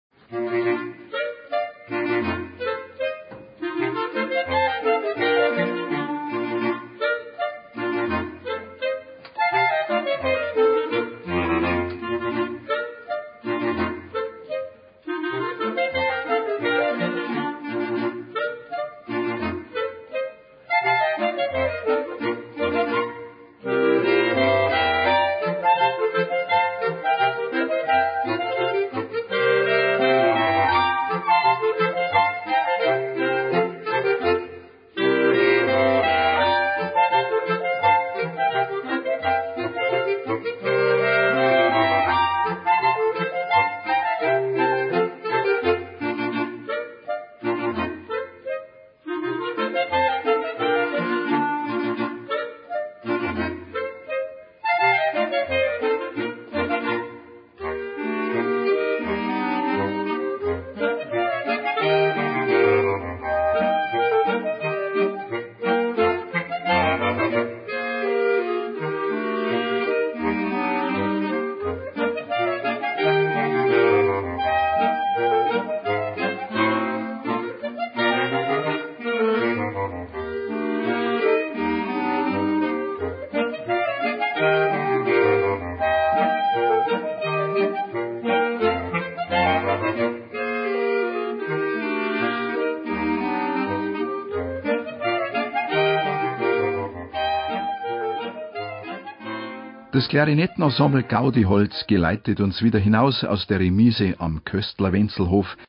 BR-Aufnahme 2025 im Freilandmuseum Neusath